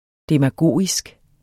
Udtale [ demaˈgoˀisg ]